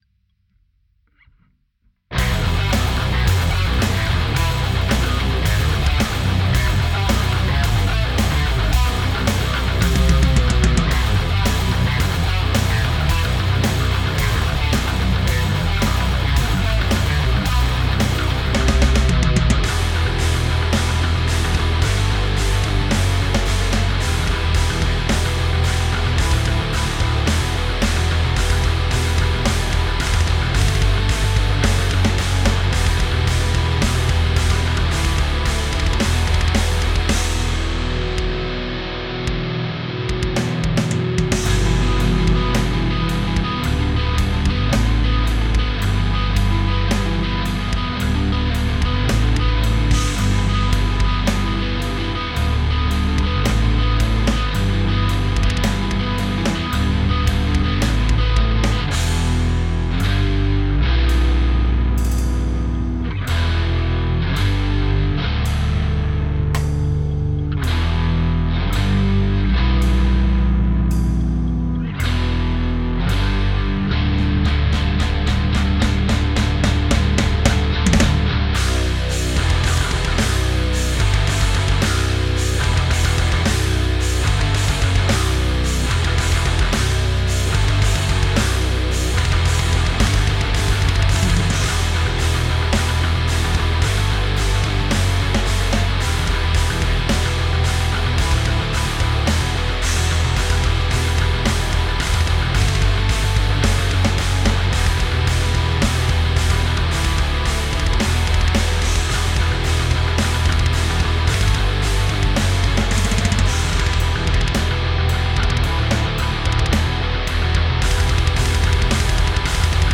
Zeigt was ihr könnt!: Metal Style
War son "Grundsatz" einer neuen Songidee, aber mangels Band nie irgendwie umgesetzt. War aber ein passendes Experiment, um mein damals gekauftes BIAS AMP Plugin in Ableton zu testen Dürfte Drop-H sein, Gitarre durch BIAS AMP und extra IR Loader.
Und Drums mit Midi programmiert und durch irgendein Plugin gejagt.
Timing hier und da off.